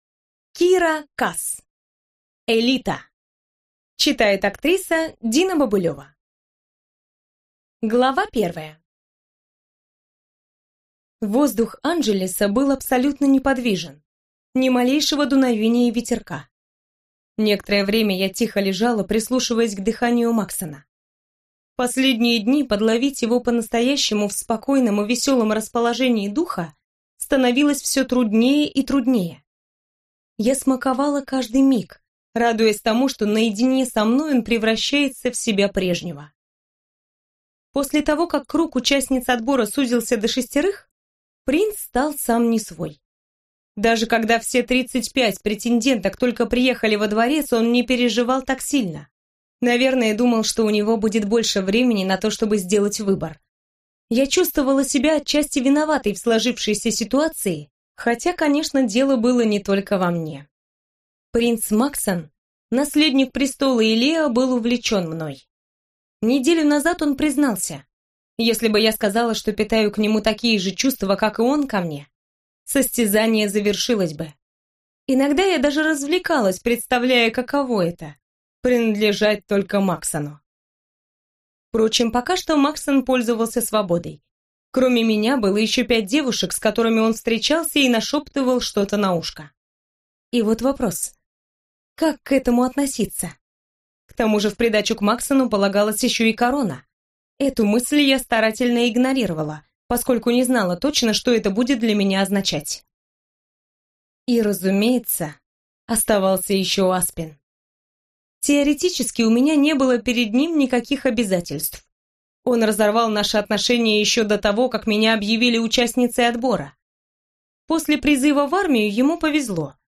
Аудиокнига «Элита» в интернет-магазине КнигоПоиск ✅ Зарубежная литература в аудиоформате ✅ Скачать Элита в mp3 или слушать онлайн